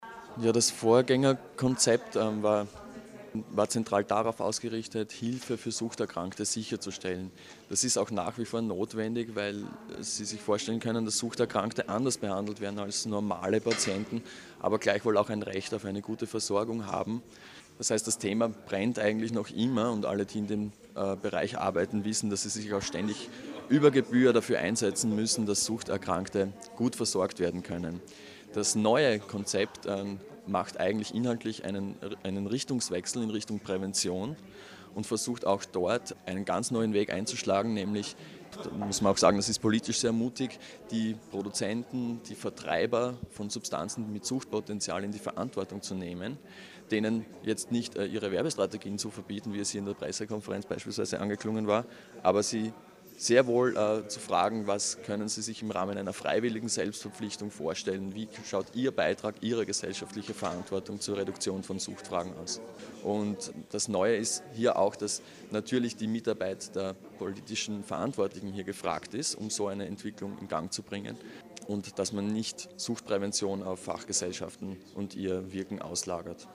O-Ton: Die neue steirische Suchtpolitik